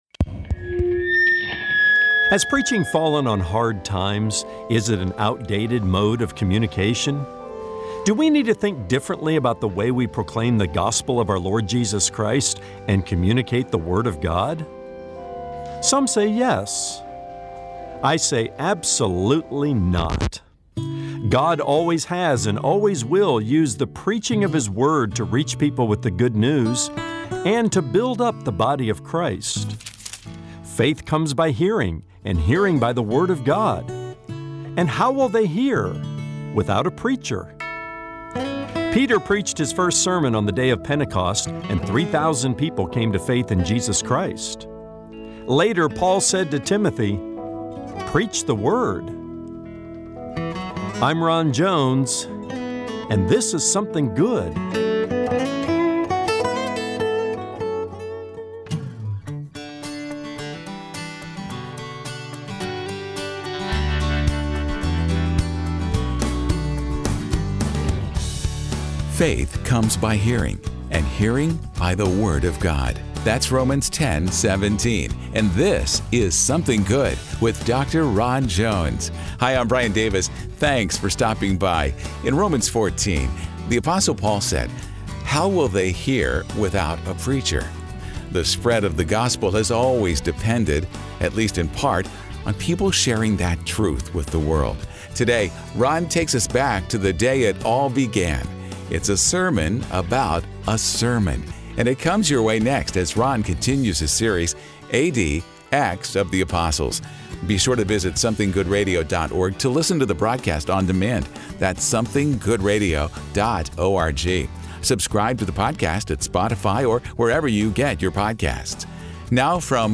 It’s a sermon about a sermon, and it starts right now.